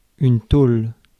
Ääntäminen
IPA: /tol/